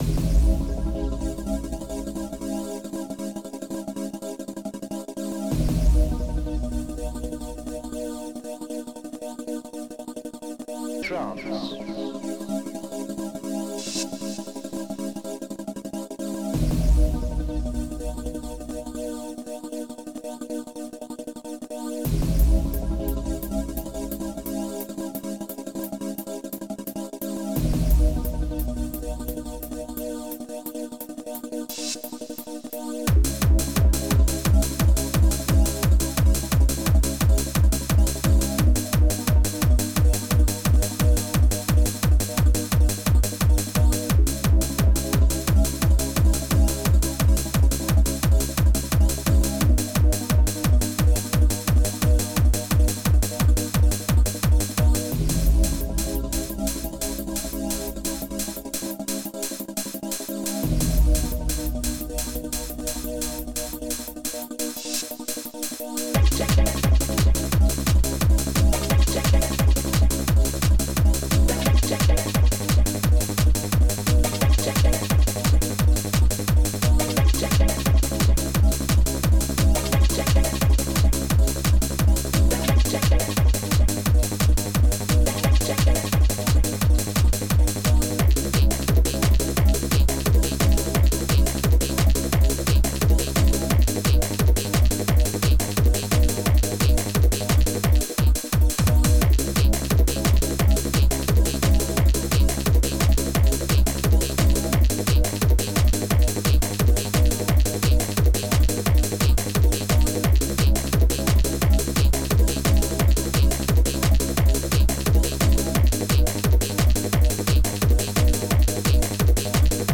Protracker Module
2 channels